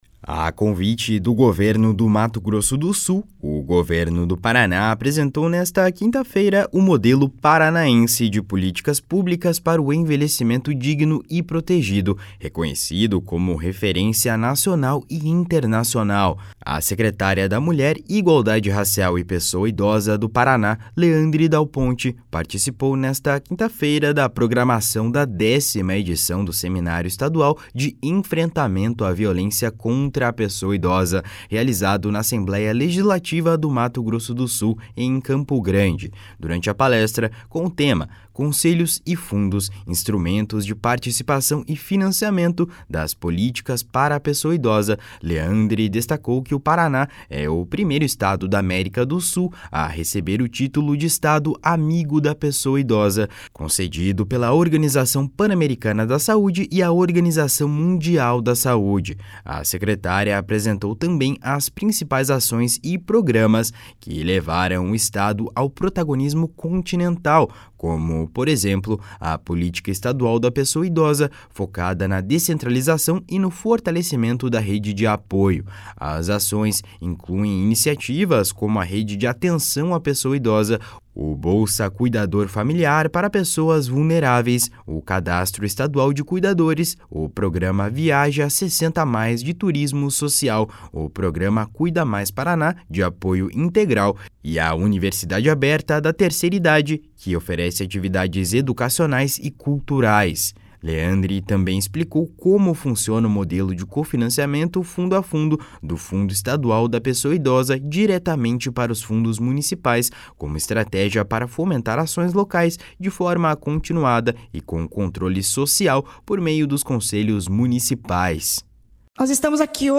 Leandre também explicou como funciona o modelo de cofinanciamento fundo a fundo – do Fundo Estadual da Pessoa Idosa diretamente para os fundos municipais – como estratégia para fomentar ações locais de forma continuada e com controle social por meio dos conselhos municipais // SONORA LEANDRE DAL PONTE //